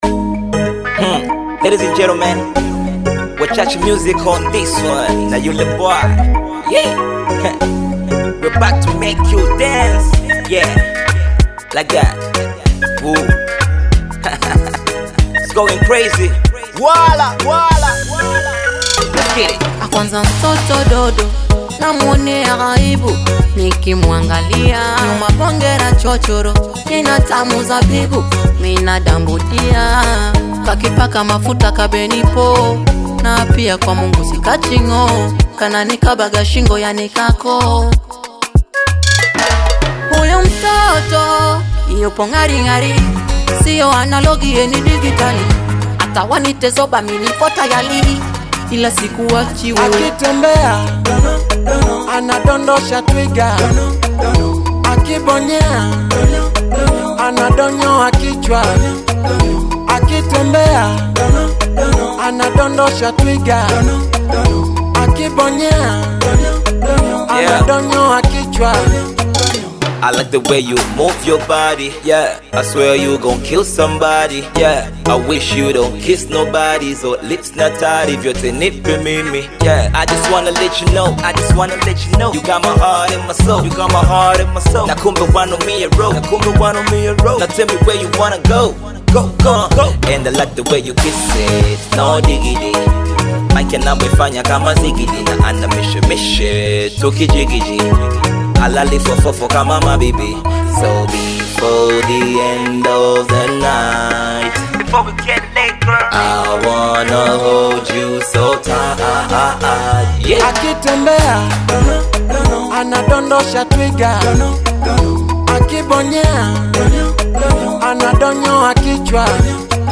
Bongo Fleva